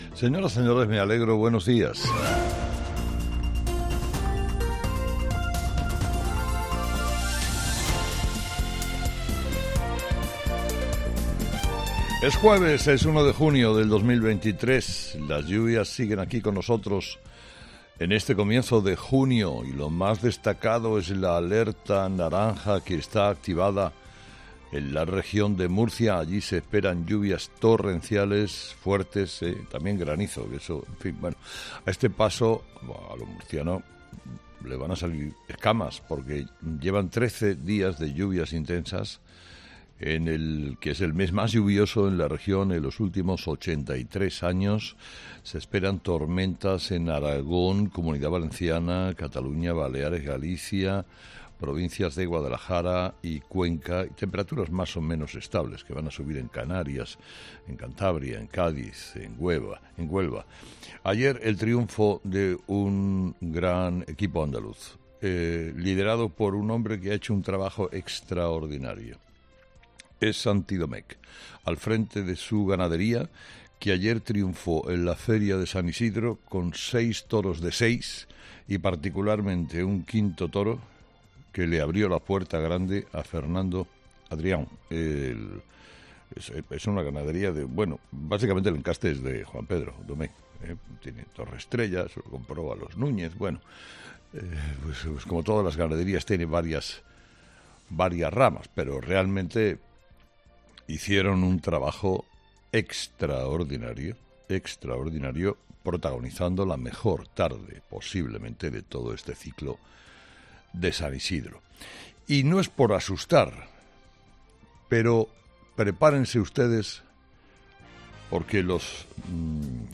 Escucha el análisis de Carlos Herrera a las 06:00 horas en Herrera en COPE este jueves 1 de junio de 2023